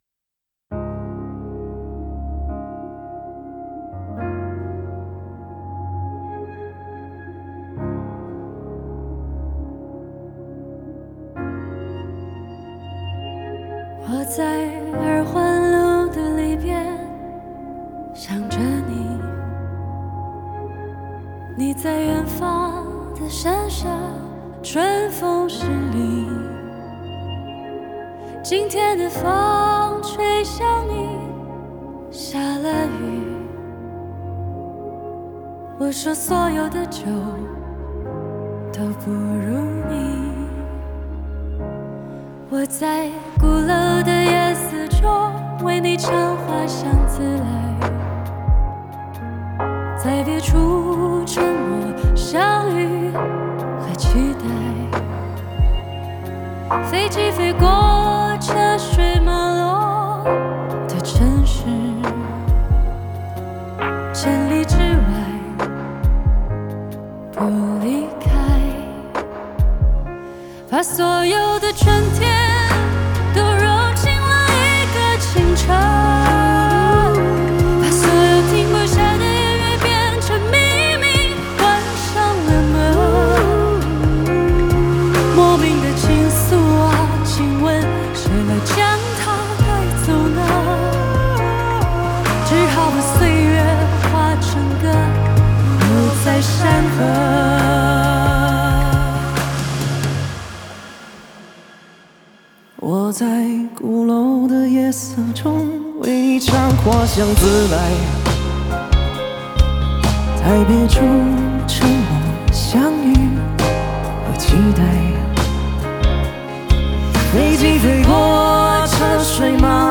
Ps：在线试听为压缩音质节选，体验无损音质请下载完整版
(Live)